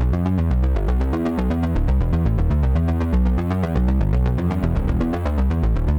Index of /musicradar/dystopian-drone-samples/Droney Arps/120bpm
DD_DroneyArp1_120-E.wav